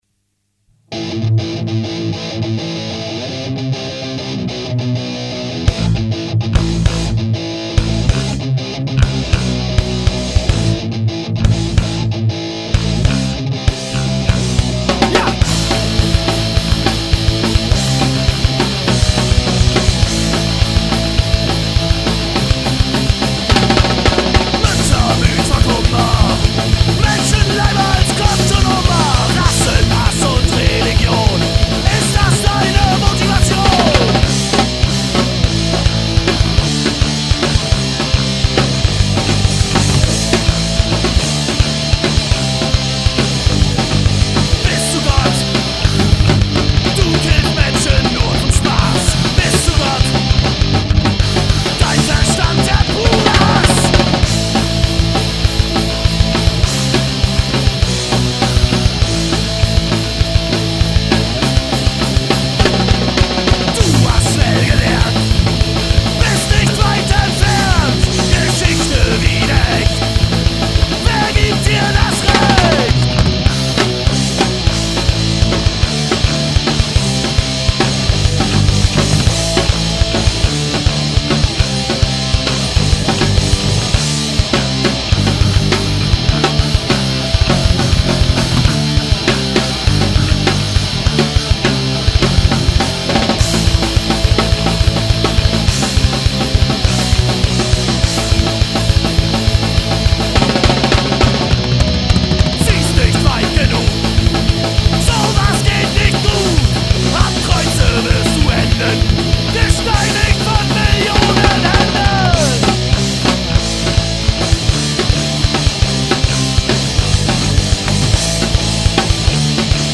Und da du nen schnellen Song wolltest hier noch einer;)